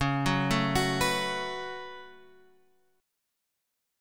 C#7sus4#5 chord {9 9 7 x 7 7} chord